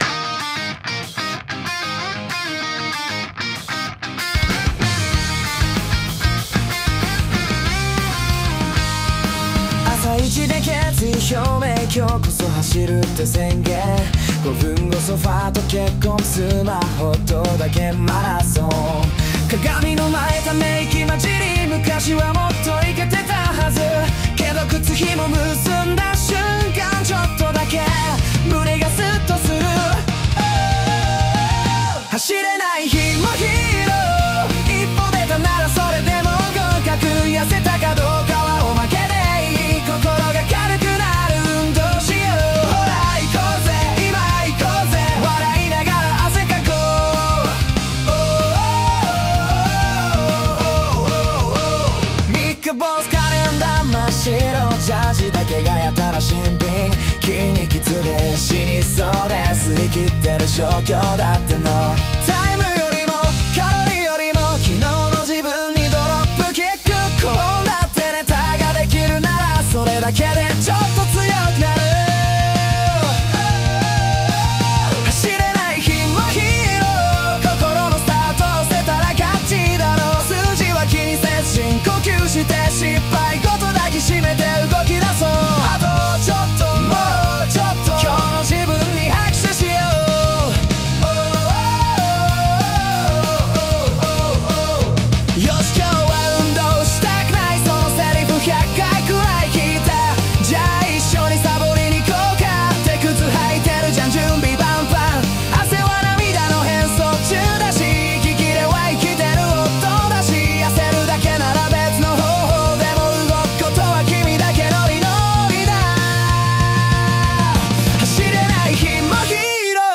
【AIと200回の格闘】 まずは歌詞に合うバンド調の曲をAIで生成。
そこでたどり着いたのが、「AIで自分の声を変換する」という方法でした。
•  ONE OK ROCK、BACK DROP BOMB、RADWIMPSの要素を掛け合わせ、WANIMAのような編成に。
•  ハモリも掛け声も、すべてカラオケボックスで自分自身で録音しました。